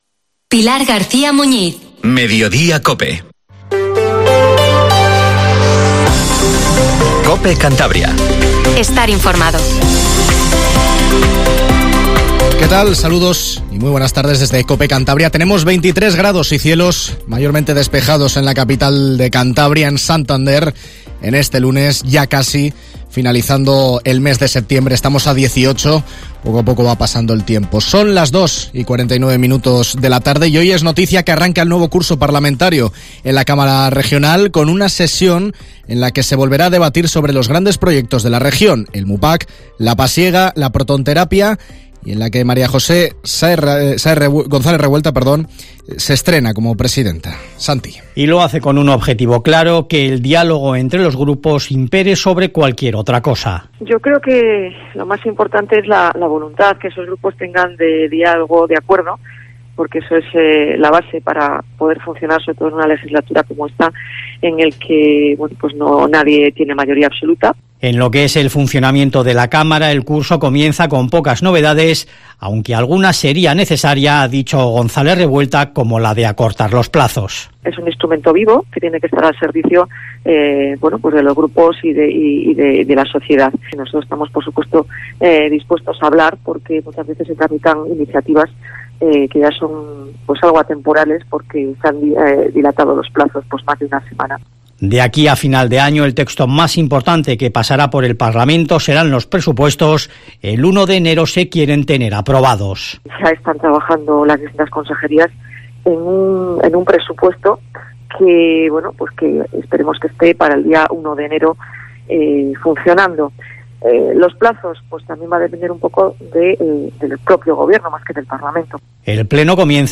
Informativo MEDIODIA en COPE CANTABRIA 14:48